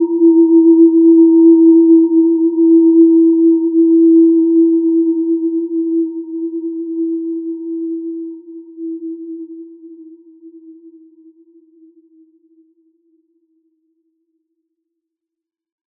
Gentle-Metallic-3-E4-p.wav